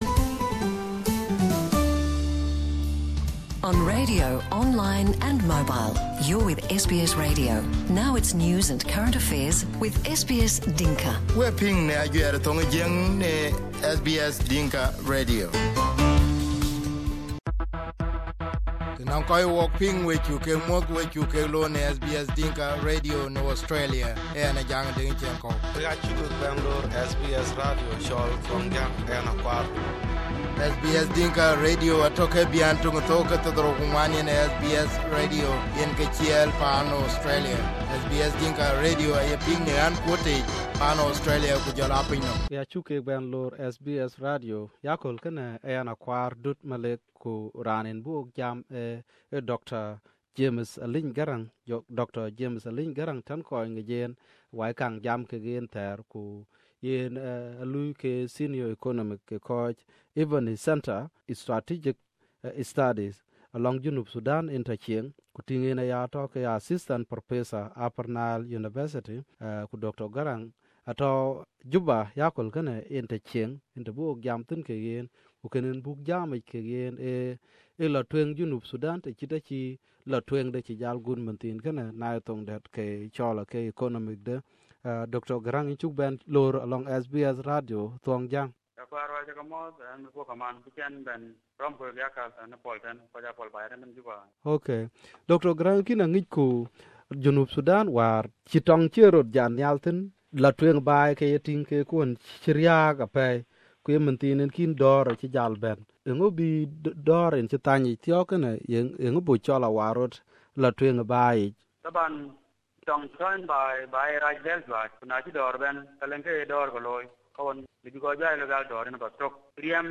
Here is the interview in Dinka